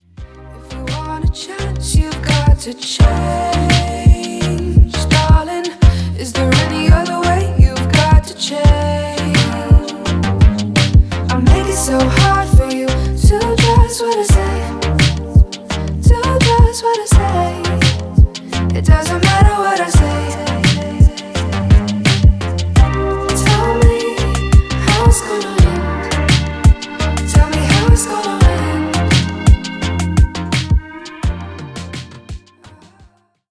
• Качество: 320, Stereo
Synth Pop
спокойные
чувственные
расслабляющие
Chill
Electropop